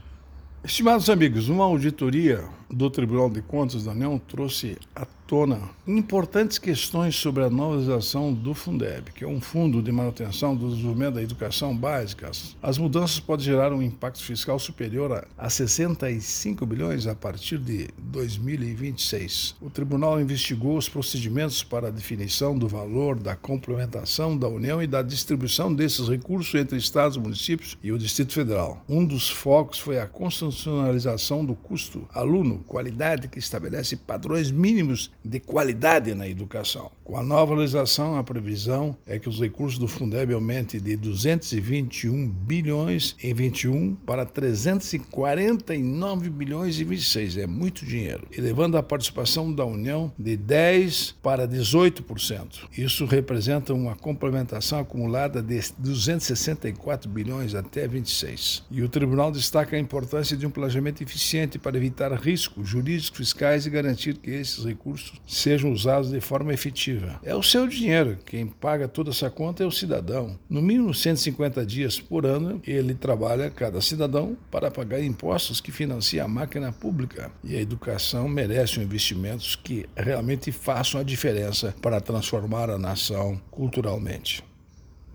Comentário de Augusto Nardes, ministro TCU.